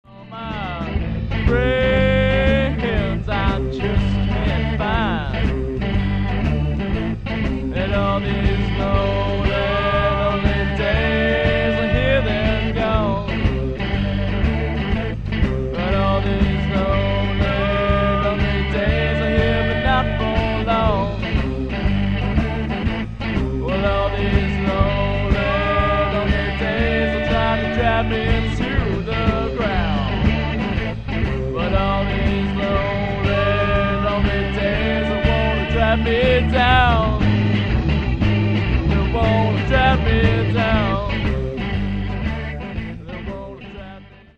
at their live performances